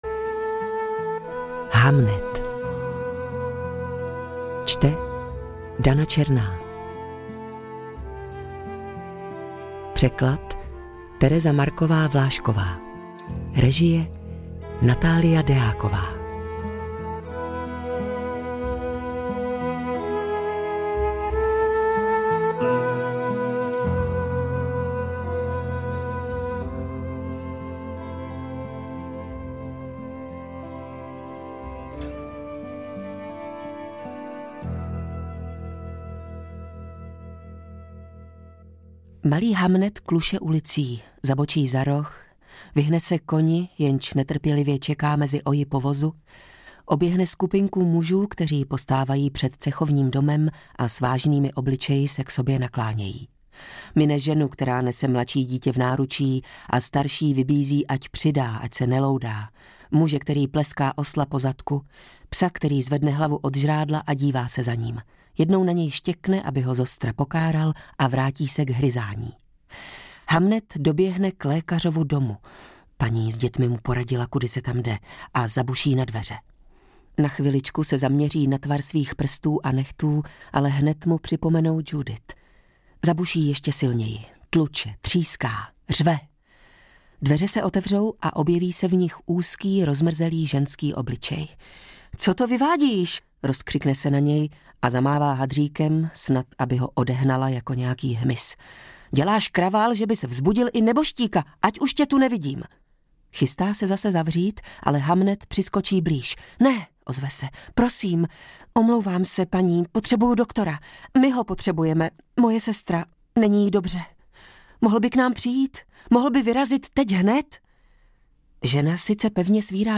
Hamnet audiokniha
Ukázka z knihy